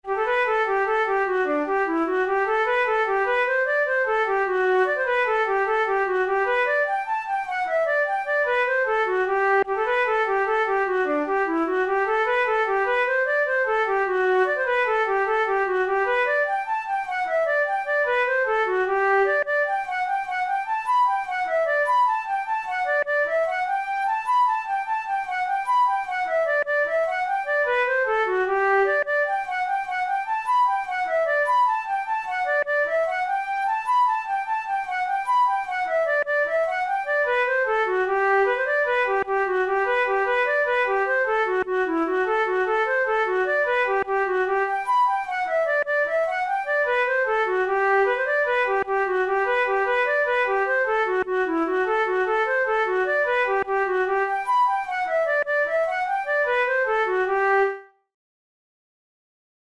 InstrumentationFlute solo
KeyG major
Time signature6/8
Tempo100 BPM
Jigs, Traditional/Folk
Traditional Irish jig